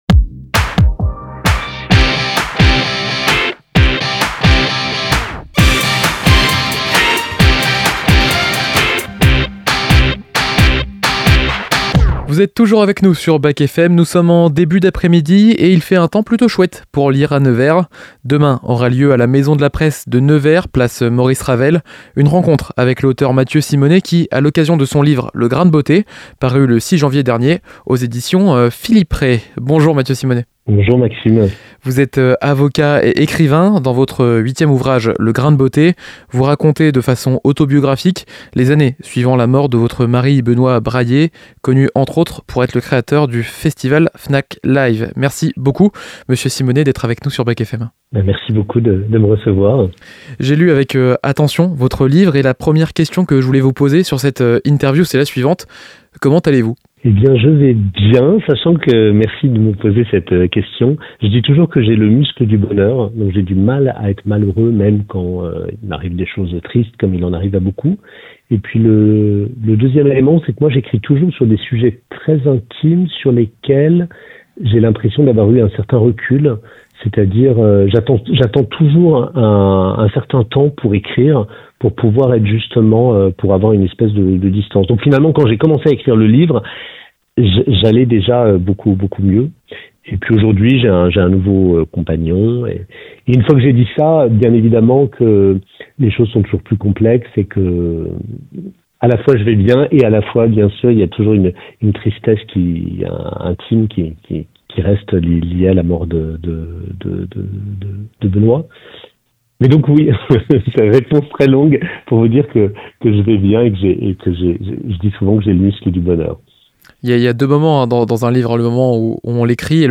C'est dans les parages - Entretien